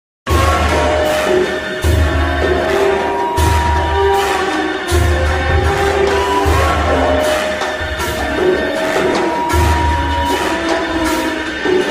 • Качество: 128, Stereo
громкие
без слов
из игр